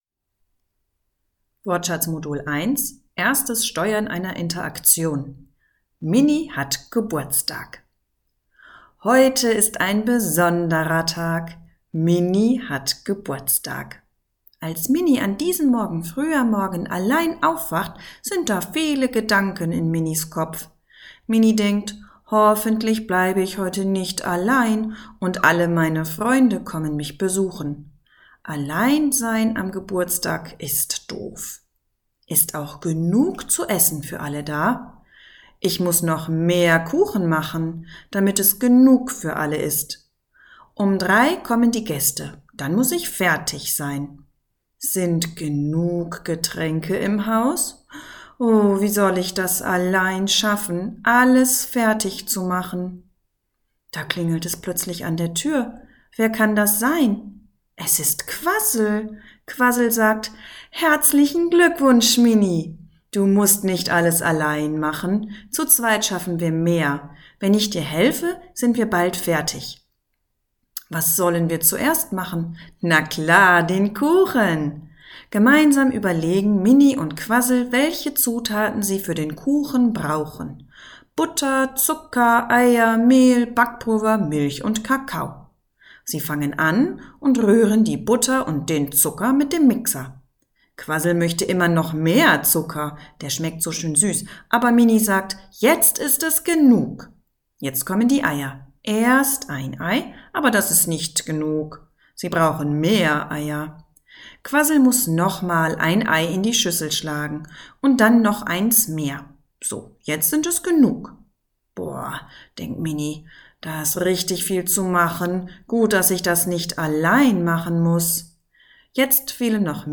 Die Aufnahmen sind mit viel Intonation und wörtlicher Rede eingesprochen, damit die Kinder ein lebhaftes Hörerlebnis haben.